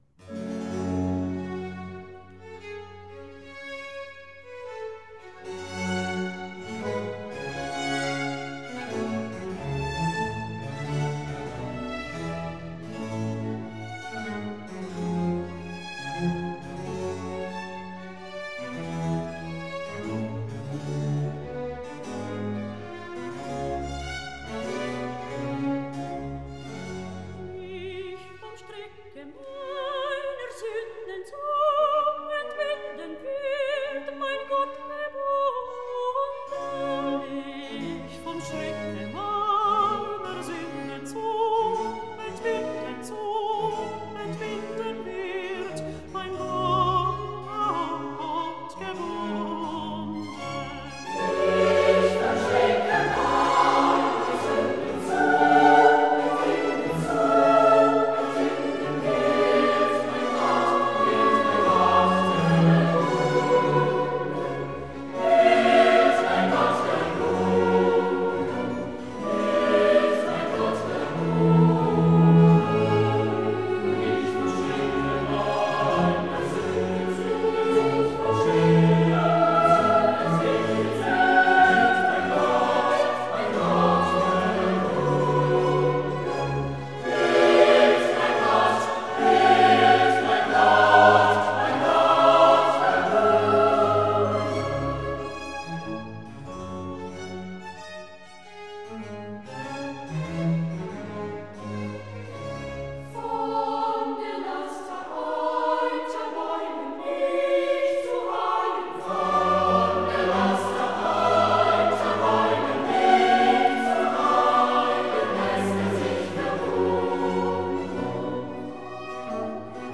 Soprano, alto, coro